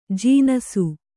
♪ jīnasu